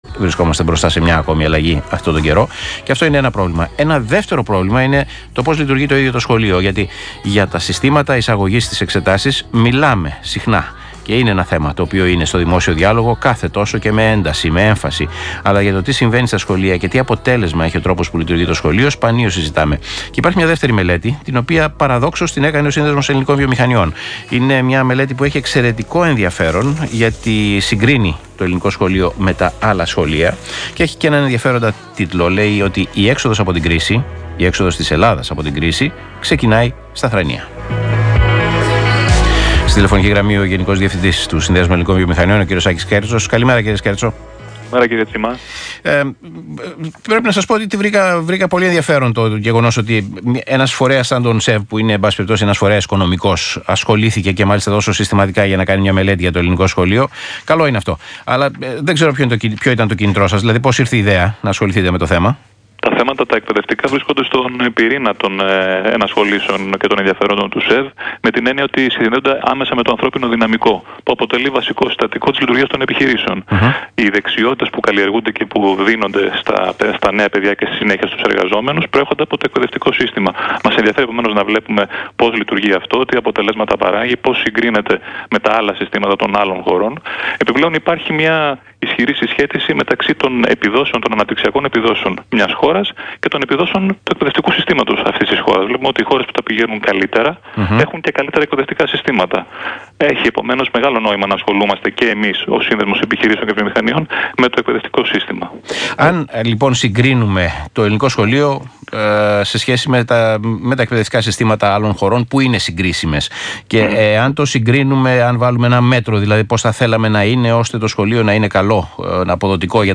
Συνέντευξη του Γενικού Διευθυντή του ΣΕΒ, κ. Άκη Σκέρτσου στον Ρ/Σ ΣΚΑΪ, 8/2/2017